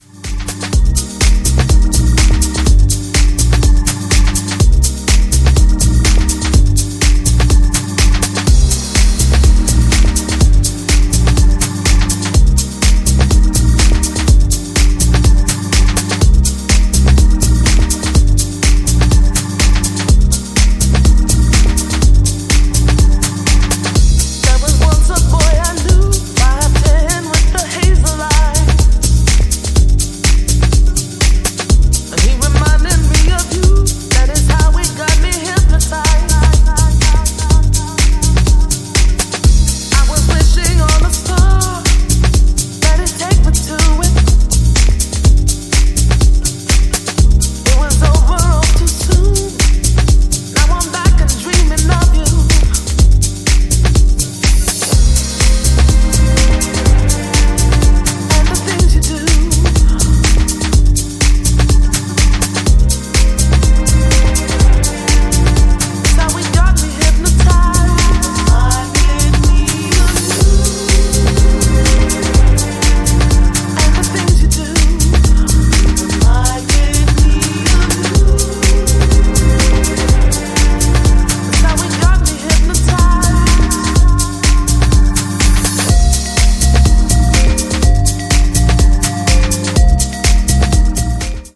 ジャンル(スタイル) DEEP HOUSE / SOULFUL HOUSE